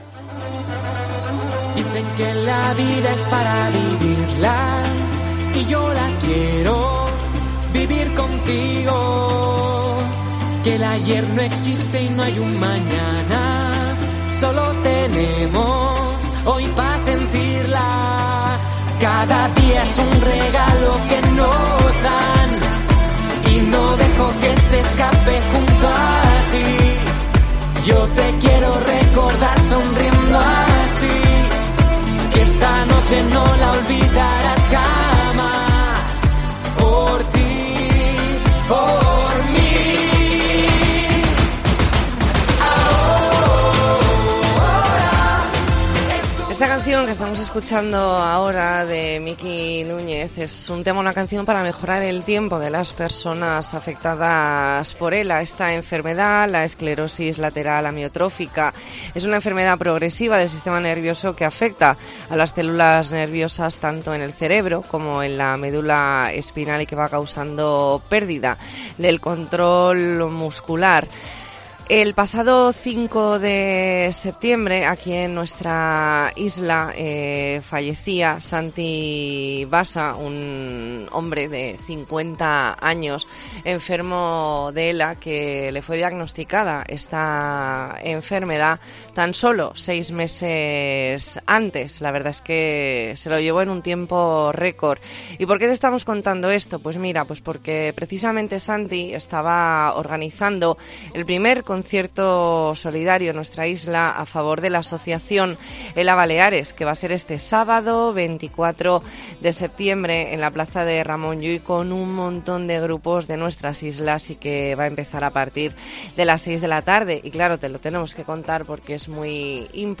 E ntrevista en La Mañana en COPE Más Mallorca, martes 20 de septiembre de 2022.